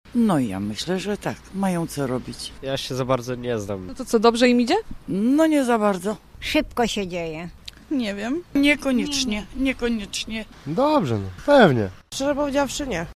Sonda RG: Gorzów nieskuteczny w pozyskiwaniu inwestorów
O tę samą kwestię pytaliśmy tez dzisiaj gorzowian w mieście: